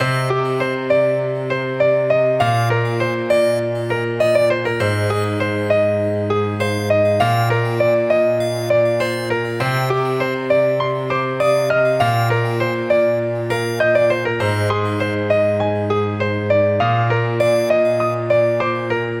Tag: 100 bpm RnB Loops Piano Loops 3.23 MB wav Key : Unknown